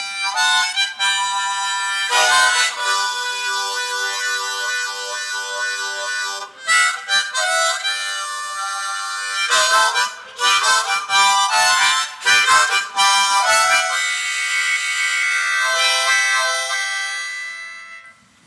口琴跑路2 130 Bpm
Tag: 130 bpm Rock Loops Harmonica Loops 636.10 KB wav Key : G